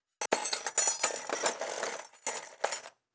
SFX_Metal Sounds_10.wav